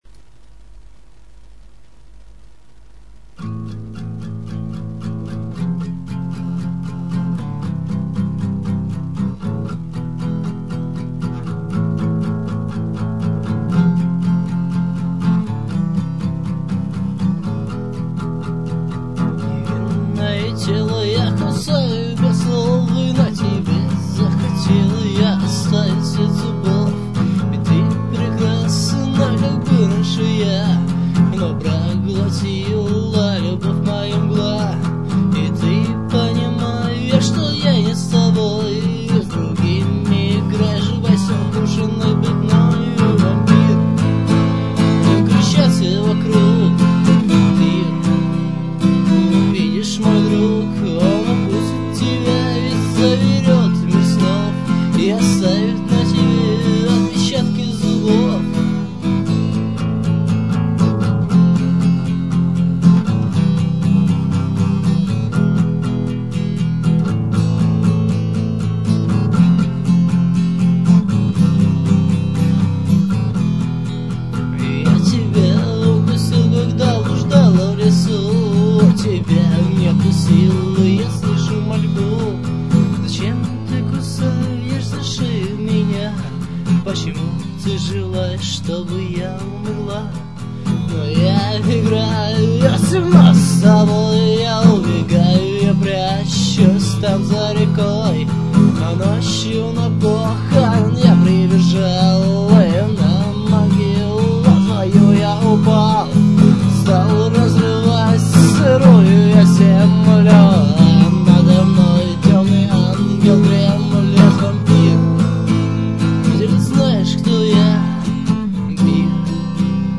Наш одмин играет на гитаре)))